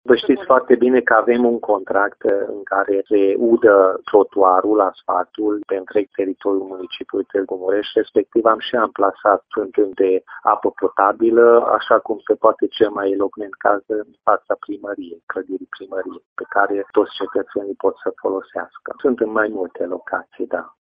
Viceprimarul Municipiului Tîrgu-Mureș, Peti Andras: